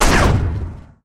poly_shoot_nuke.wav